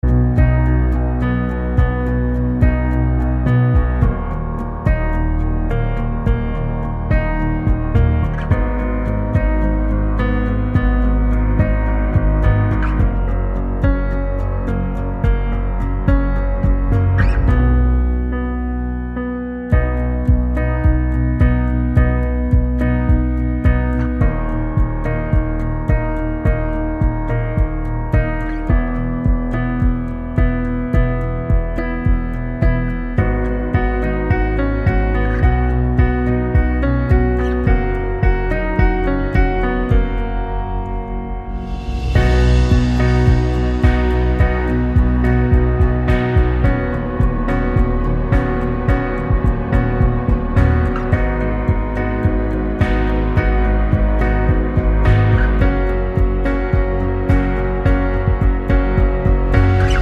no Backing Vocals Pop (2010s) 3:26 Buy £1.50